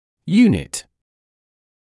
[‘juːnɪt][‘юːнит]узел, элемент (в т.ч. один элемент, состоящий из нескольких составляющих, но рассматриваемый как одно целое)